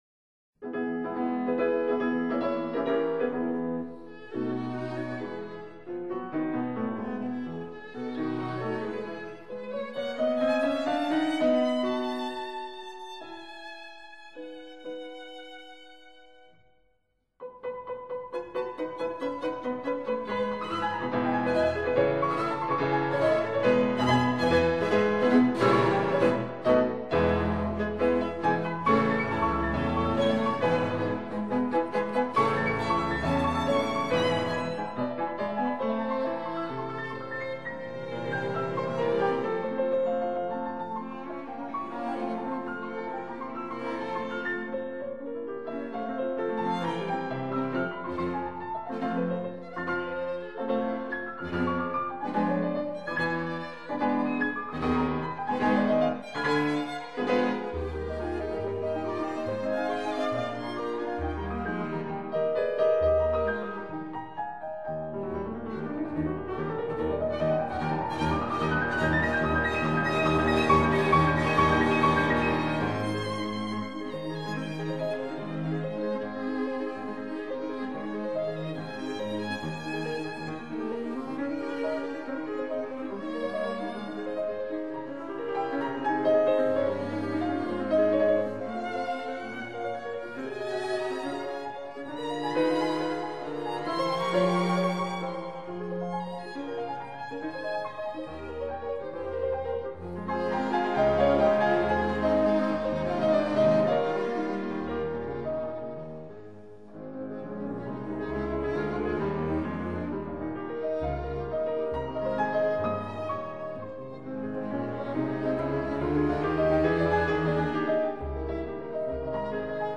鋼琴五重奏輯